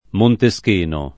[ monte S k % no ]